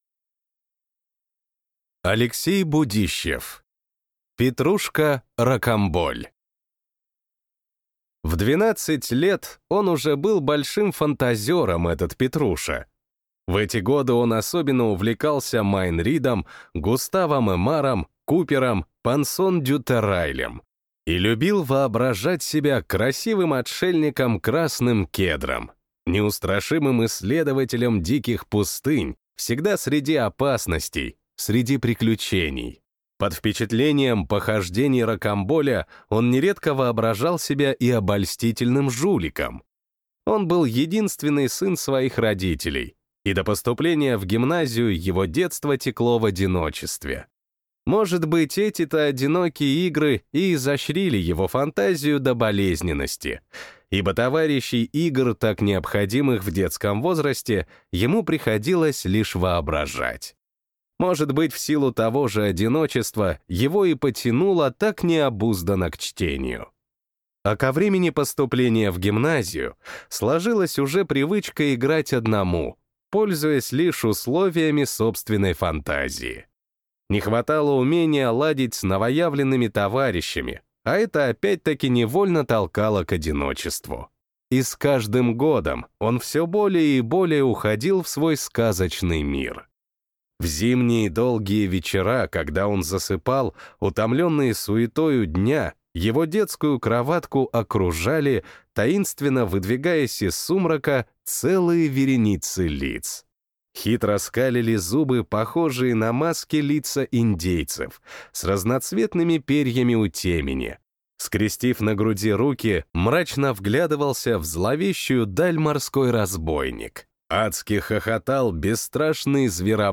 Аудиокнига Петруша Рокамболь | Библиотека аудиокниг
Прослушать и бесплатно скачать фрагмент аудиокниги